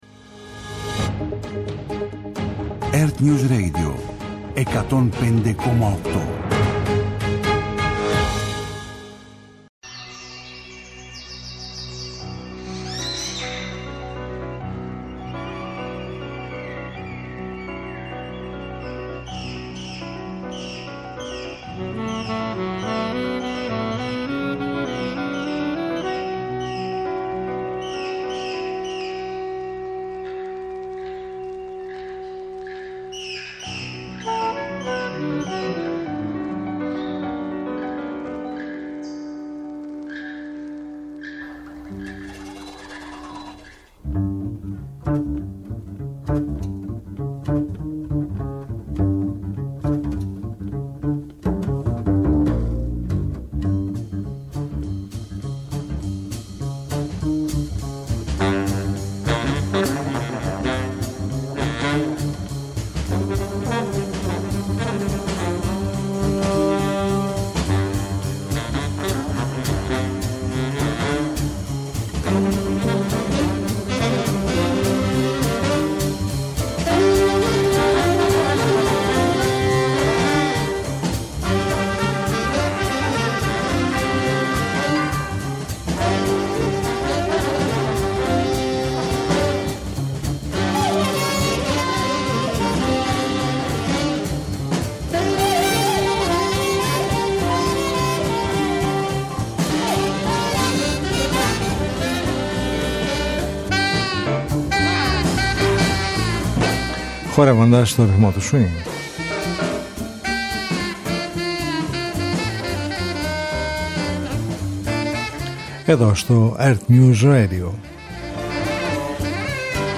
Μια εβδομαδιαία ωριαία συνάντηση με τις διάφορες μορφές της διεθνούς και της ελληνικής jazz σκηνής, κάθε Σάββατο στις 23:00 στο ΕΡΤnews Radio 105.8.